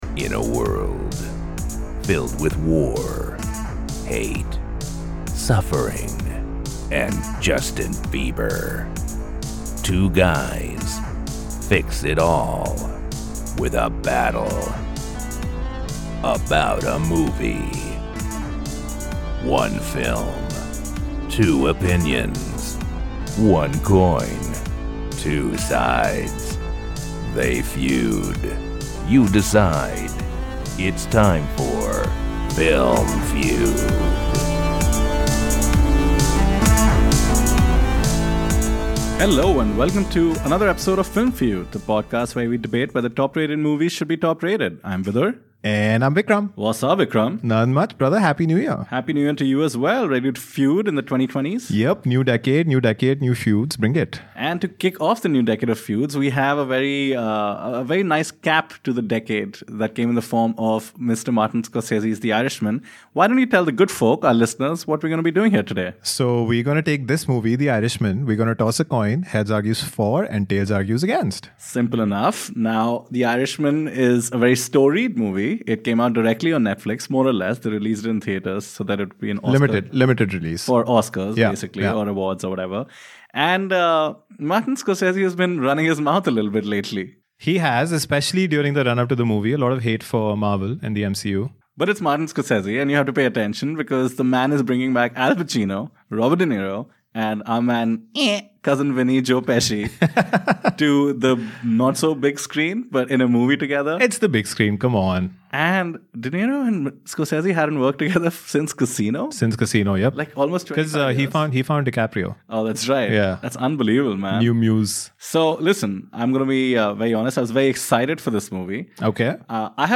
On this week's feud, the boys debate Martin Scorsese's - The Irishman. Off the bat, de-aging and the ageing effects the movie causes (it's long) are brought into question, beyond which the conversation veers between high praise and insulting rhetoric. The guys find middle ground over Joe Pesci and his understated performance and immediately jumps to disagreements over Robert De Niro's performance.